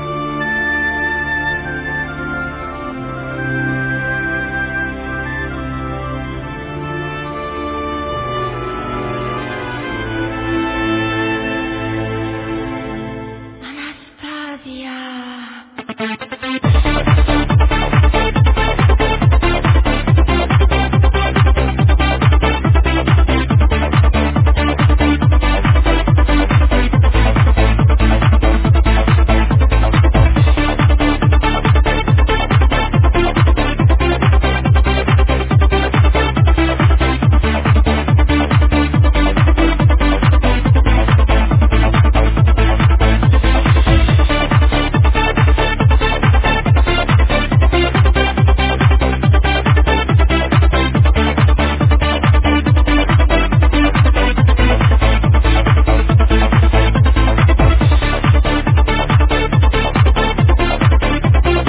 (tacky dance track!)